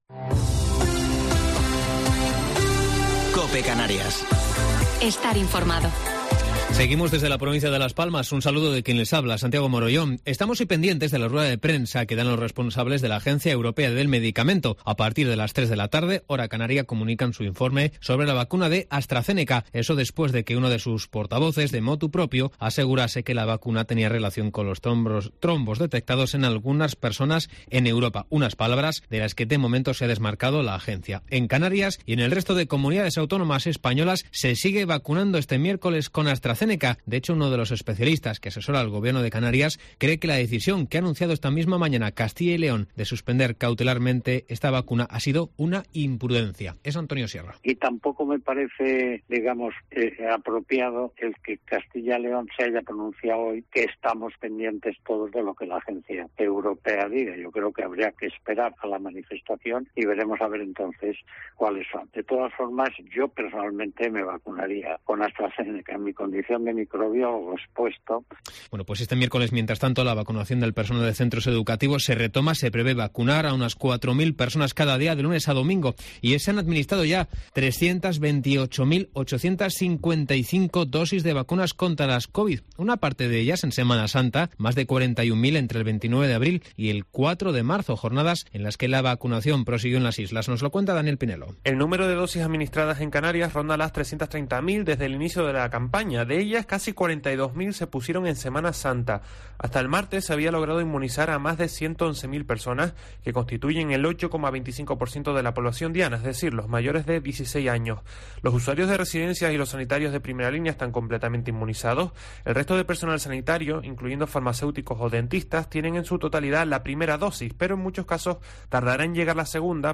Gran Canaria Informativo local 7 de Abril del 2021